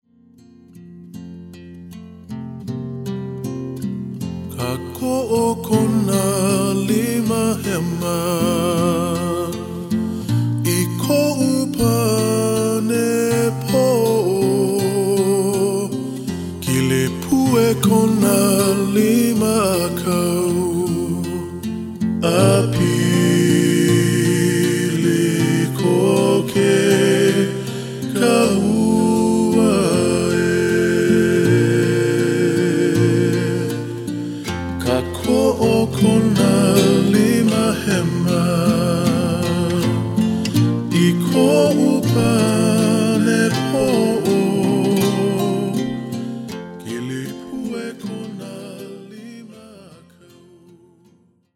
with earnest confidence